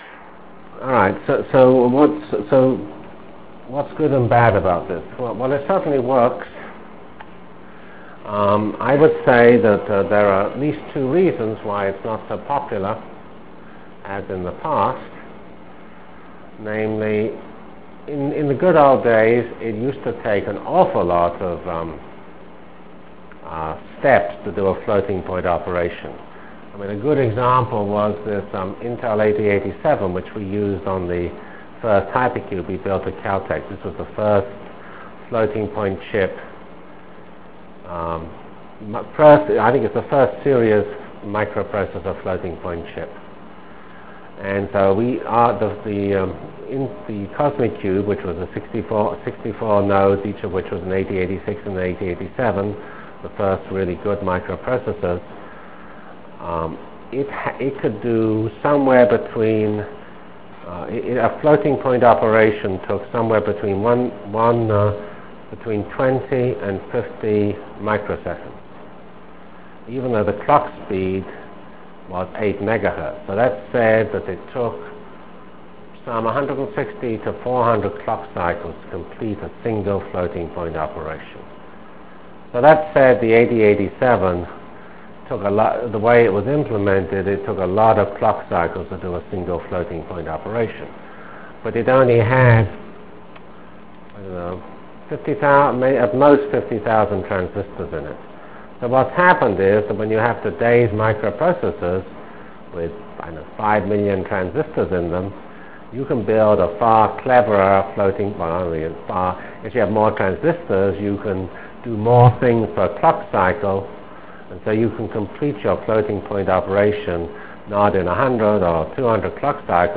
From CPS615-Lecture on Performance(end) and Computer Technologies(start) Delivered Lectures of CPS615 Basic Simulation Track for Computational Science -- 10 September 96.